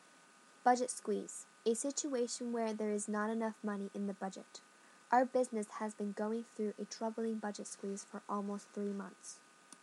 英語ネイティブによる発音は下記のURLから聞くことができます。